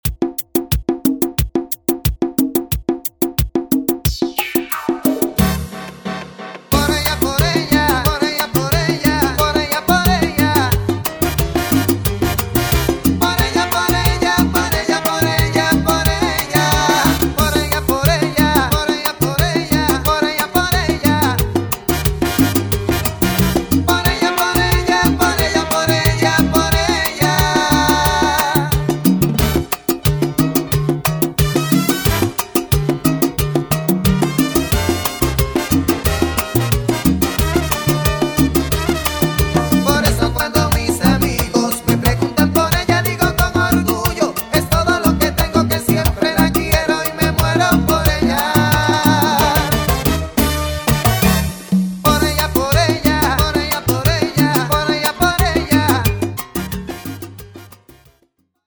salsa remix